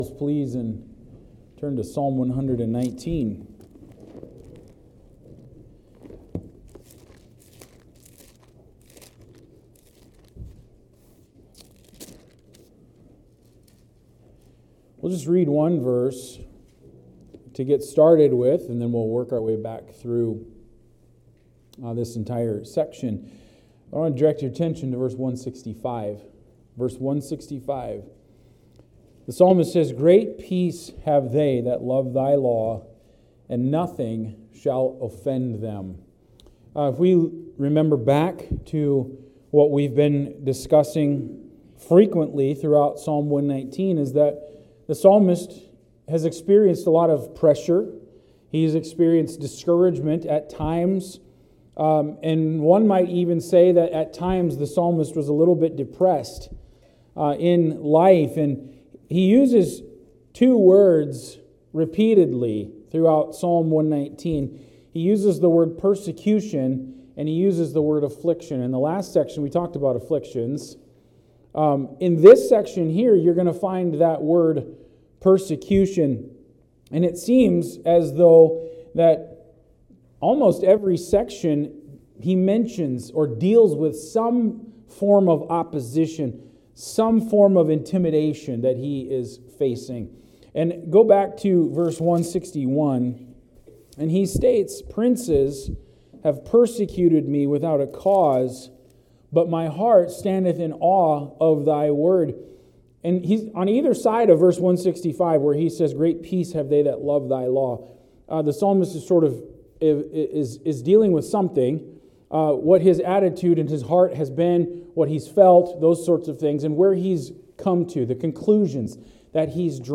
Sermons | Plack Road Baptist Church podcast